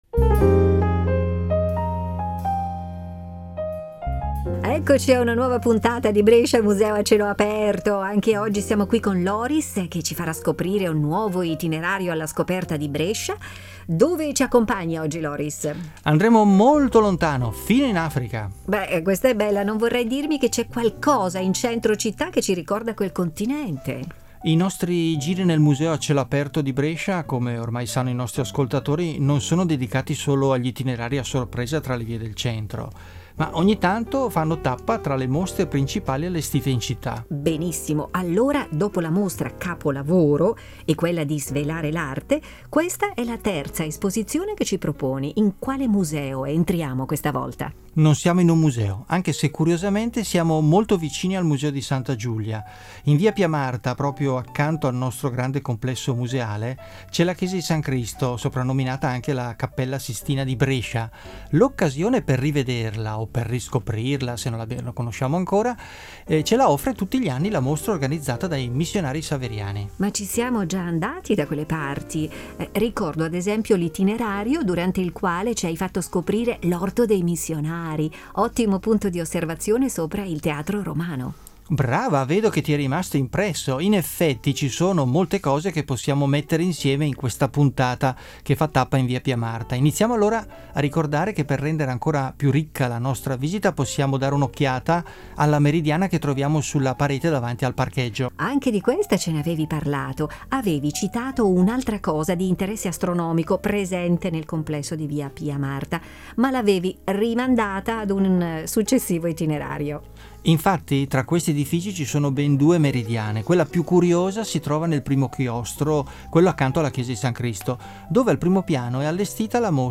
audio-guida e itinerari per passeggiare tra i musei